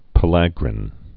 (pə-lăgrĭn, -lā-, -lägrĭn)